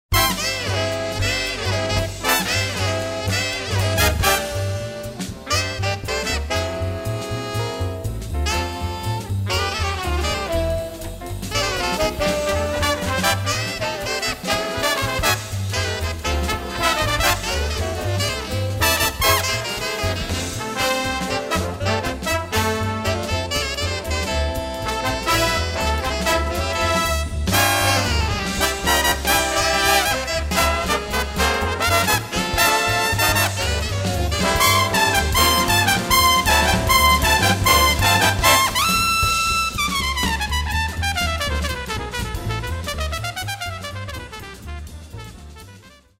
bright treatment
Trombone
Keyboards
String Bass